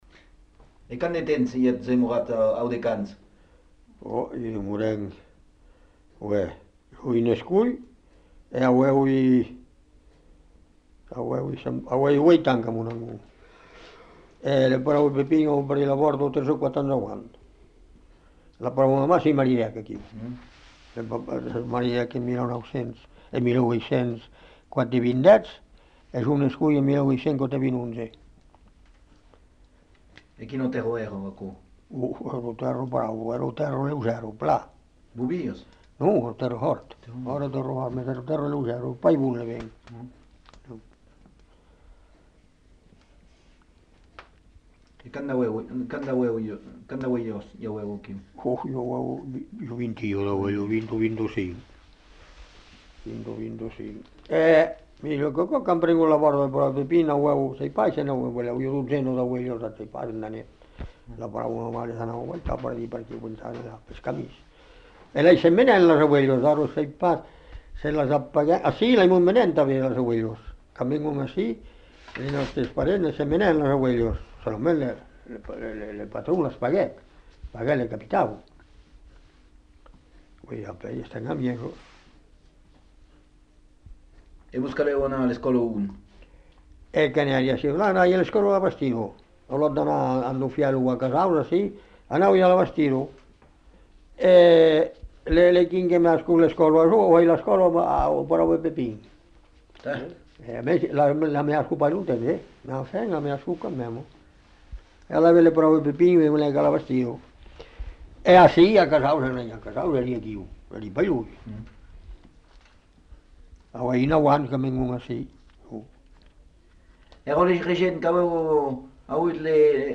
Lieu : Cazaux-Savès
Genre : récit de vie